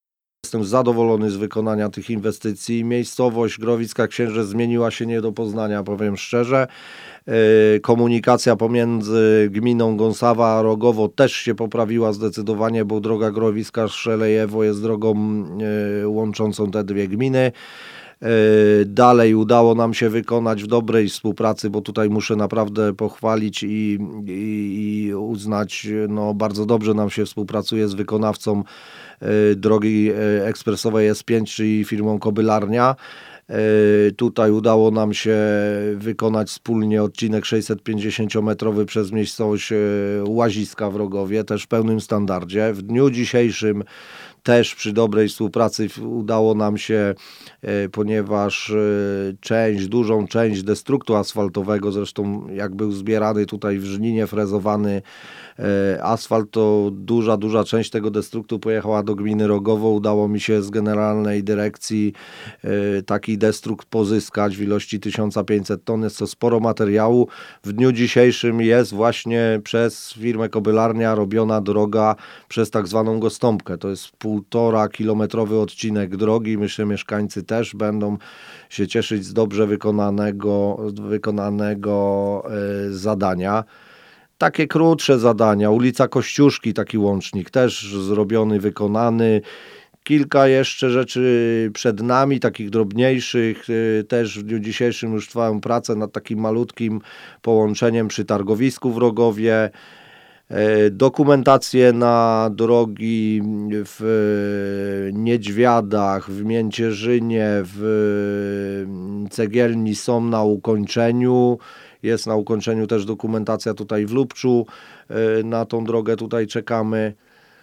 Są też mniejsze odcinki dróg lokalnych, o których mówi wójt Tomasz Michalczak.